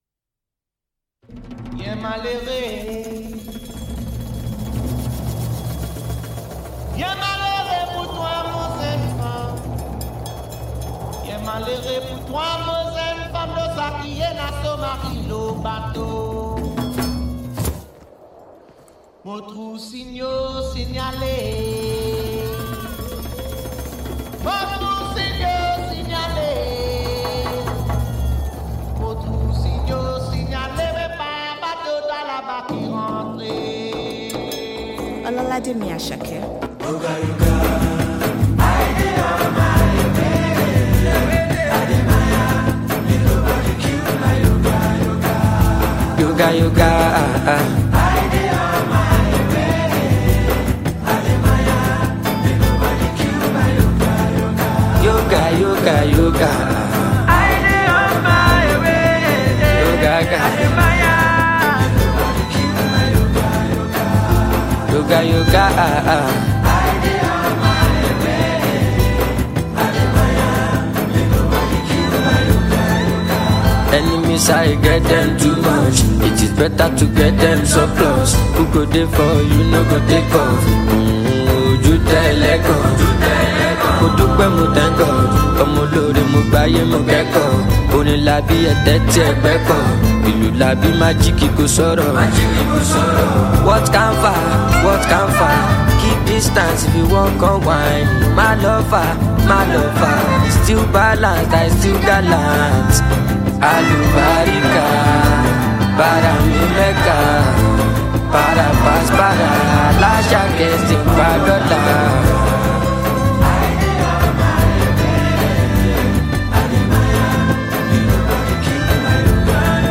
Well renowned Nigerian artist and performer
gbedu